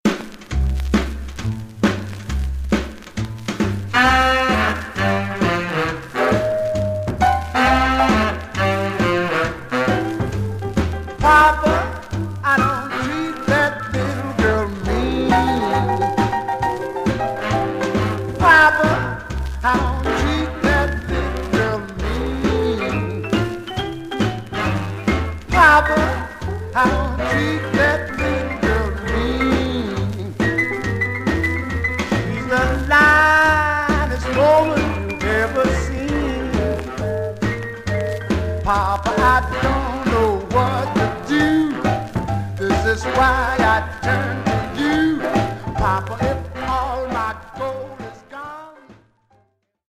Stereo/mono Mono
Some surface noise/wear
Rythm and Blues